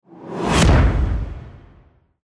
衝撃音（IMPACT）
バーン
ズーン
ドカッ
se_impact.mp3